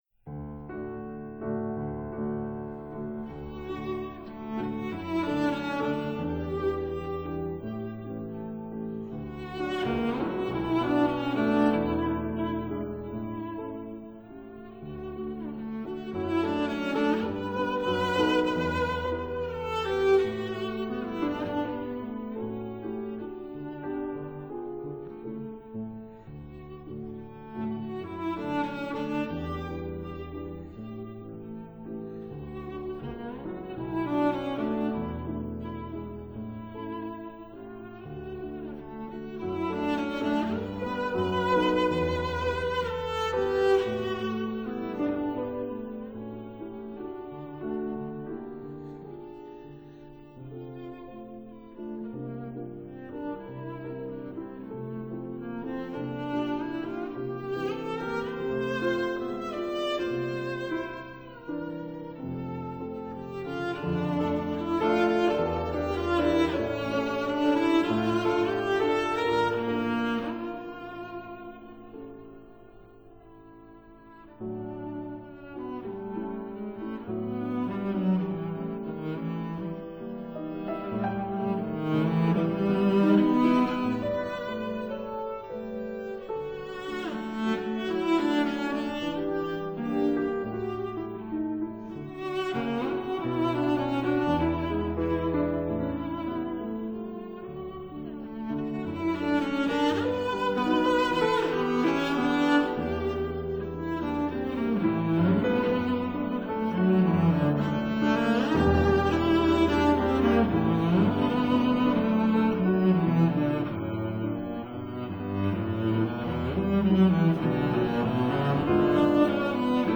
cello
piano